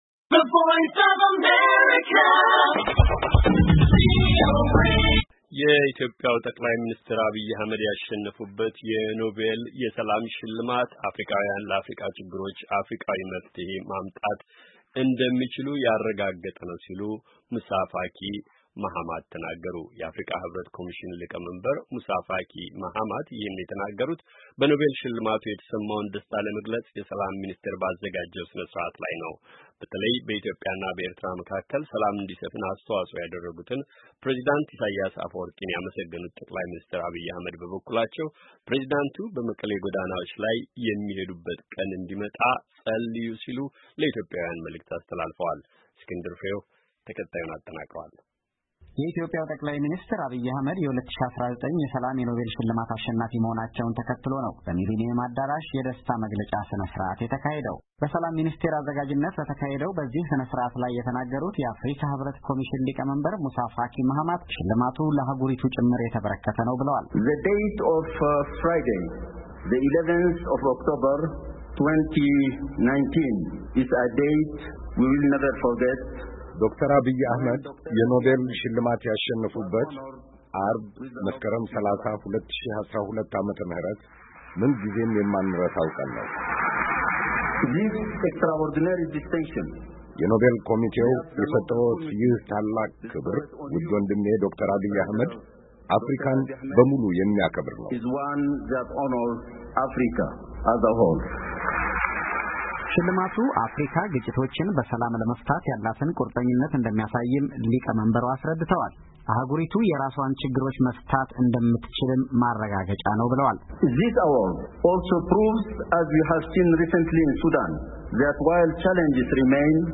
የአፍሪካ ሕብረት ኮሚሽን ሊቀመንበር ሙሳፋኪ ማሃማት ይህን የተናገሩት በኖቤል ሽልማቱ የተሰማውን ደስታ ለመግለፅ የሰላም ሚኒስቴር ባዘጋጀው ሥነ- ስርዓት ላይ ነው።